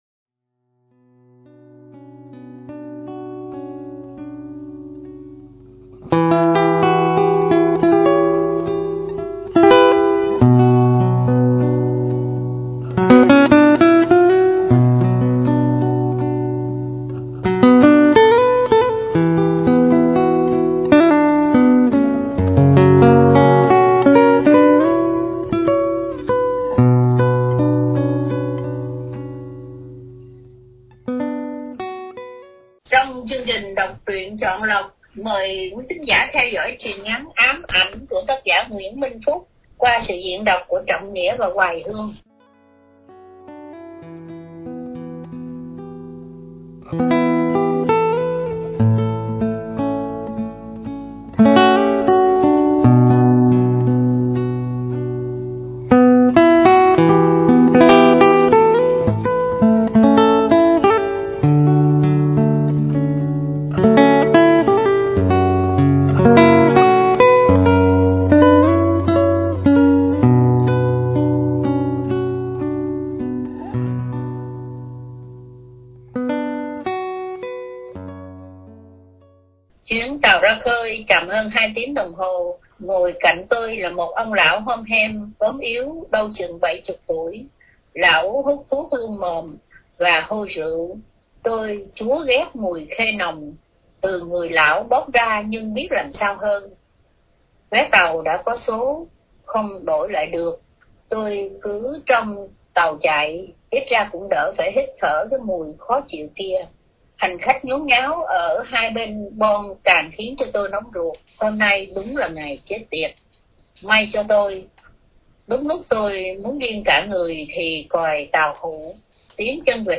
Đọc Truyện Chọn Lọc – Truyện Ngắn ‘Ám Ảnh ‘- Nguyễn Minh Phúc – Radio Tiếng Nước Tôi San Diego